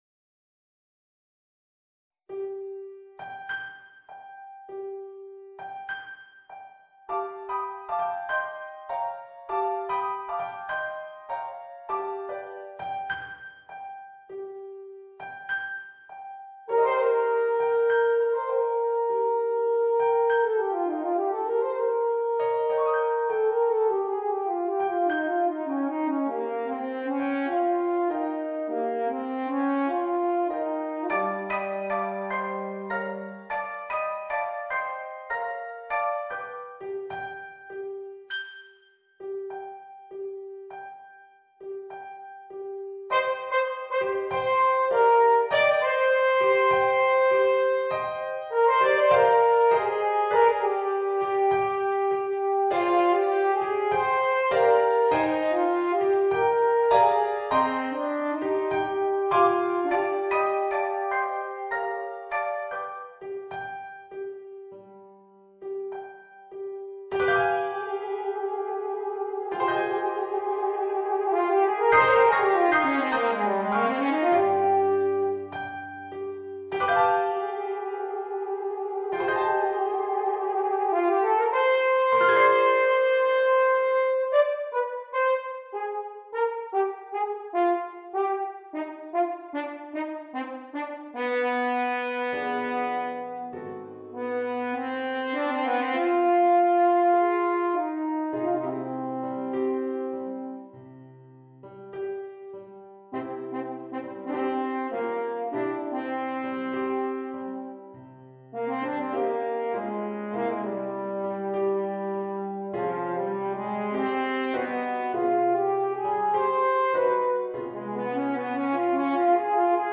Es-Horn & Klavier